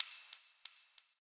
tanbarin.ogg